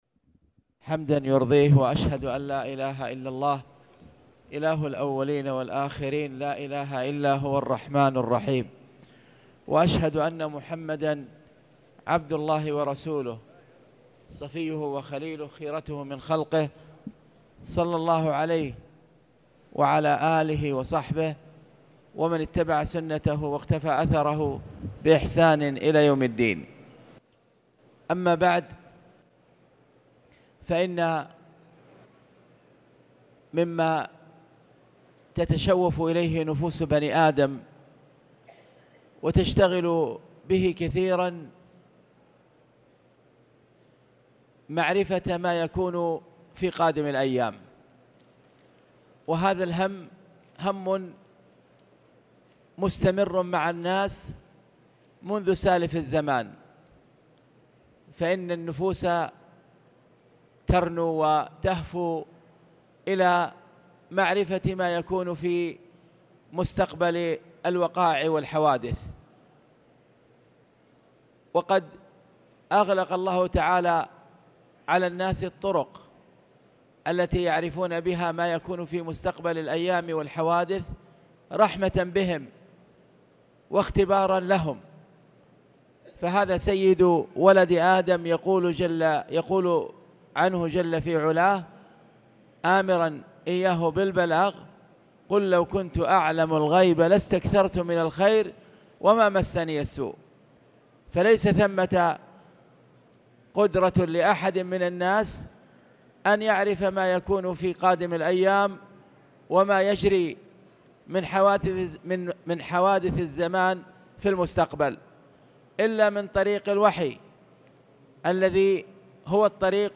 تاريخ النشر ٨ رجب ١٤٣٨ هـ المكان: المسجد الحرام الشيخ